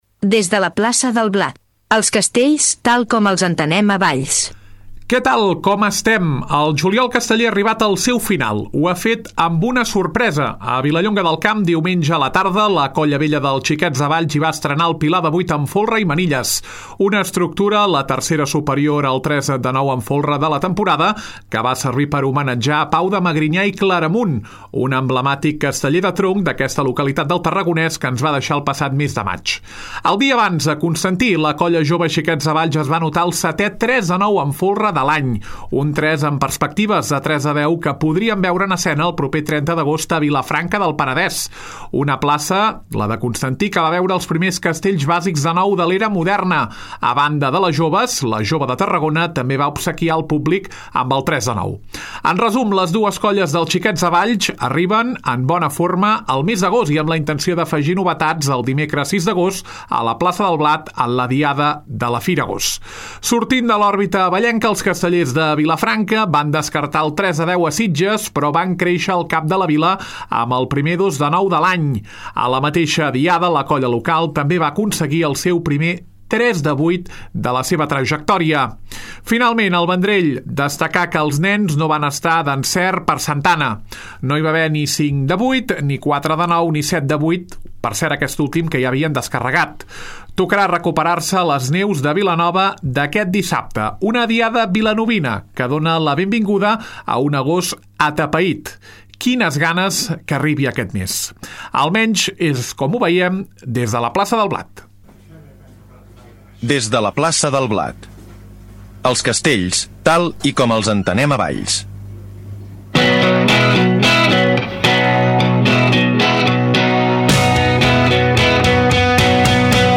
Setena edició de Des de la Plaça del Blat, el programa que explica els castells tal com els entenem a Valls. Tertúlia amb l’anàlisi de les diades de Vilallonga del Camp